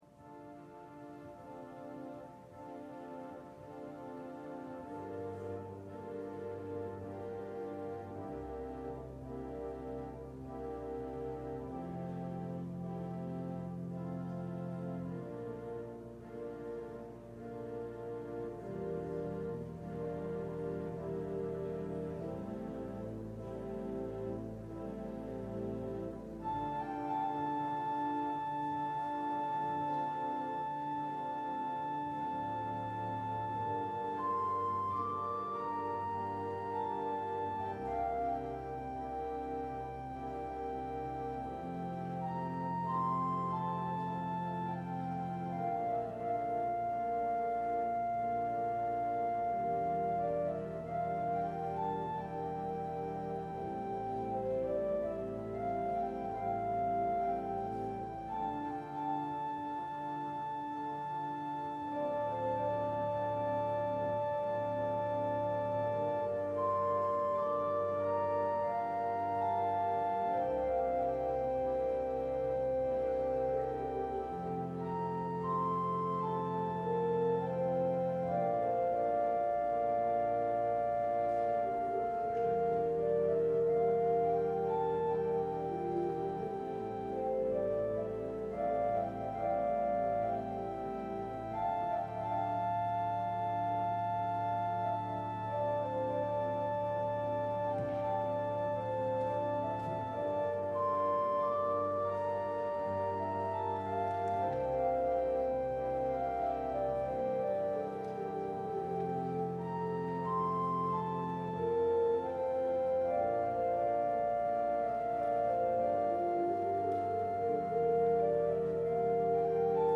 LIVE Evening Worship Service - War and Peace
Congregational singing—of both traditional hymns and newer ones—is typically supported by our pipe organ.